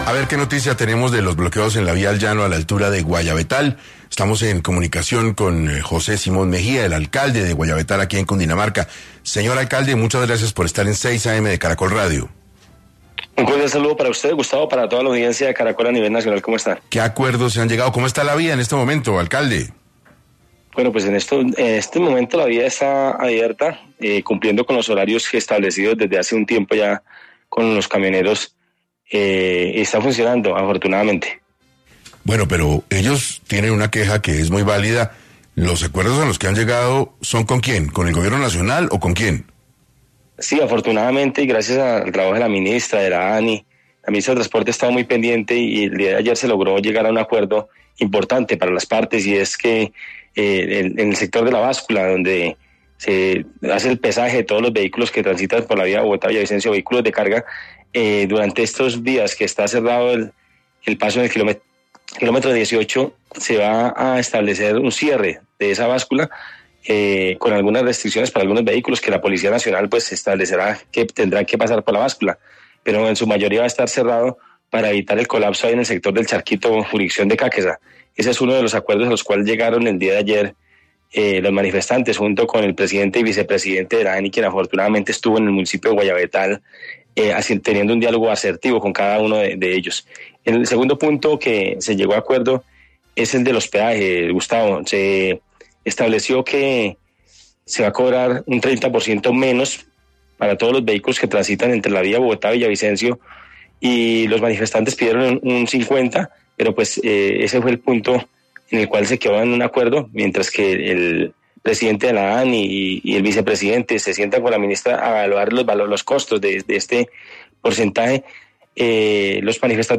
José Simón Mejía, alcalde de Guayabetal (Cundinamarca), contó en 6AM cómo el cierre de la vía al Llano afecta a su comunidad.